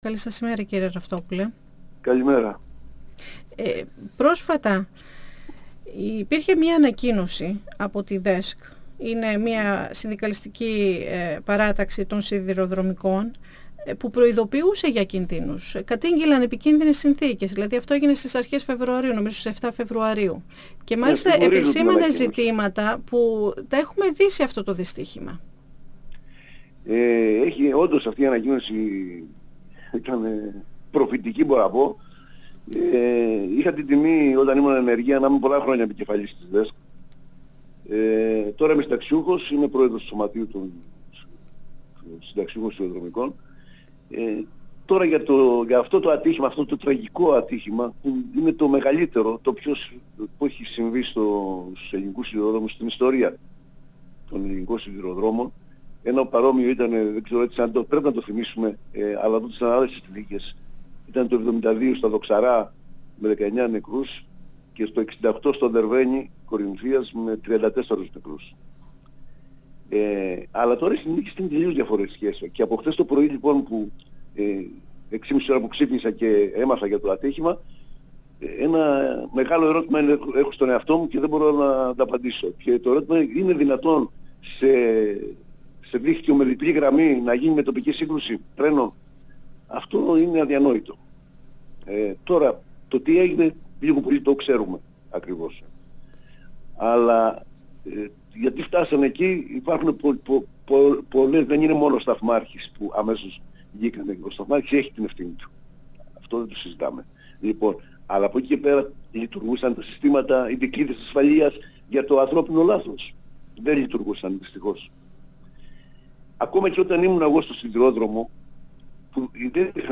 Αν λειτουργούσαν η τηλεδιοίκηση ή η φωτοσήμανση δεν θα γινόταν το δυστύχημα, γιατί θα είχε «προλάβει» το ανθρώπινο λάθος, βλέποντας το κόκκινο σηματοδότη. 102FM Συνεντεύξεις ΕΡΤ3